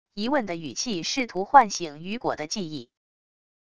疑问的语气试图唤醒雨果的记忆wav音频